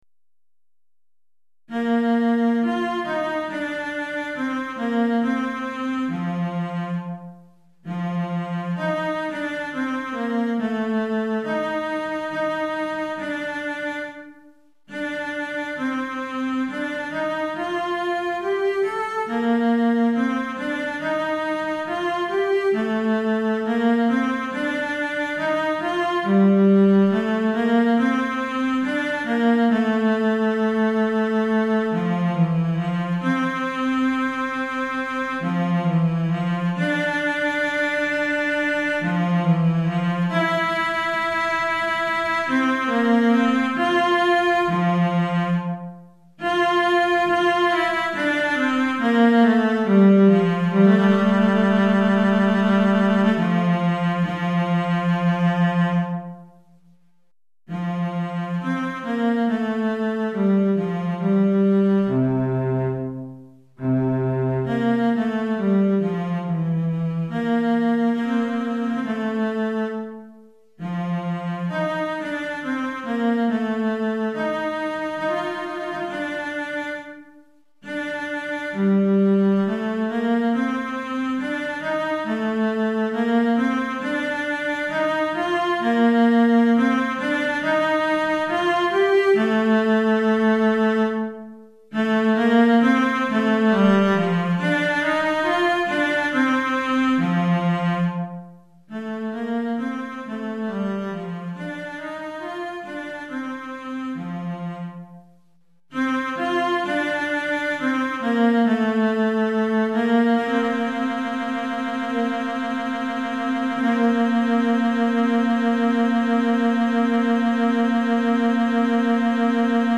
Violoncelle Solo